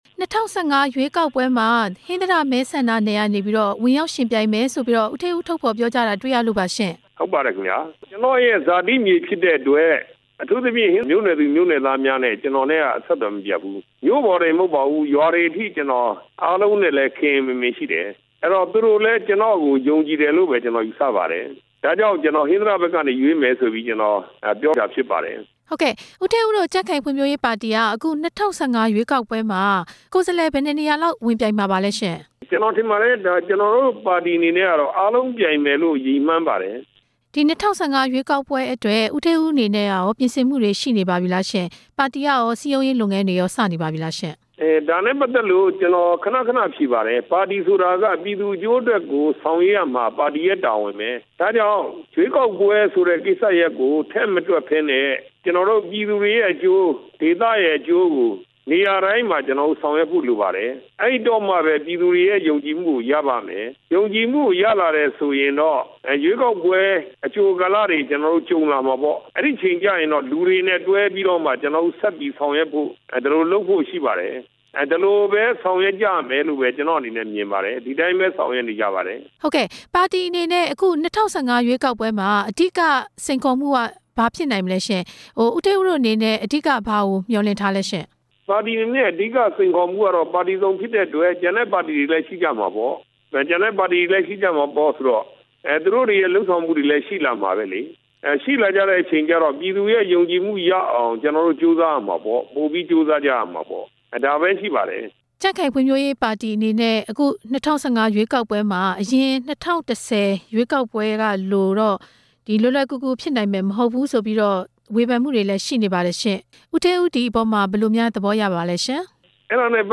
ကြံ့ခိုင်ရေးပါတီ ၂၀၁၅ ရွေးကောက်ပွဲ အလားအလာ မေးမြန်းချက်